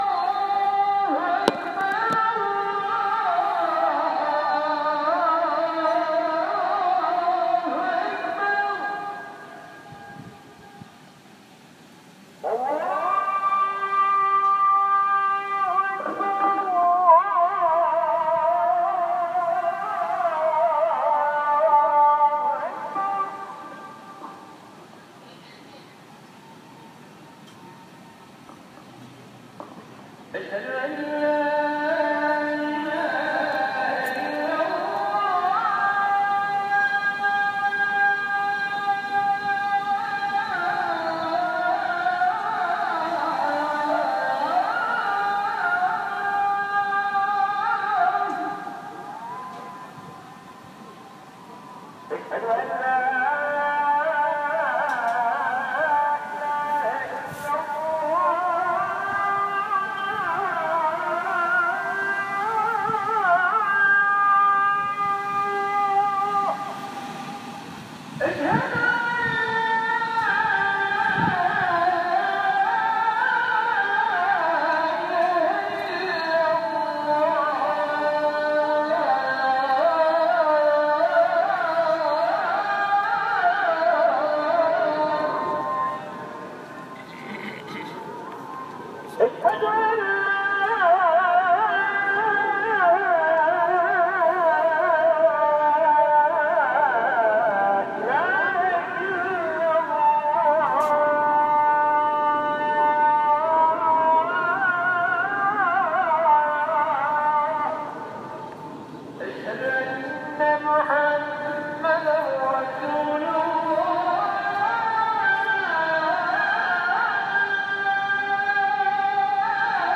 (Avant d’aller voir la mosquée bleue, j’ai eu la chance d’entendre l’appel à la prière de midi, entre Sainte-Sophie et la mosquée bleue. On dirait qu’ils se répondent.
chant-des-muezzins-mosquecc81e-bleue-et-ste-sophie.m4a